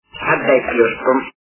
» Звуки » Люди фразы » Голос - Отдай плюшку
При прослушивании Голос - Отдай плюшку качество понижено и присутствуют гудки.